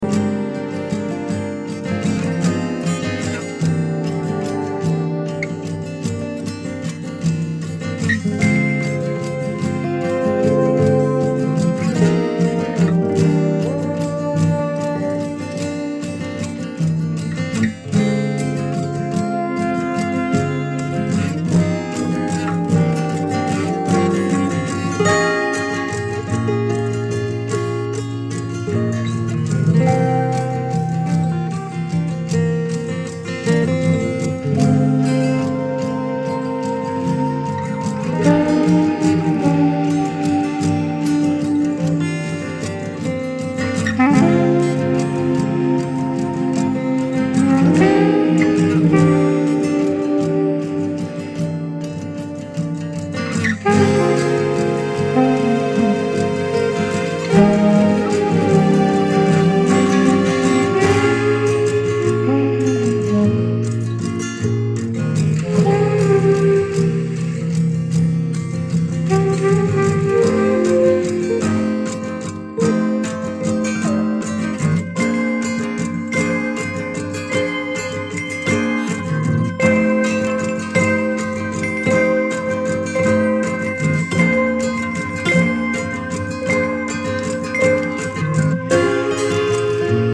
フォークを中心にダブやバレアリック的なサウンドをも展開する極上作品！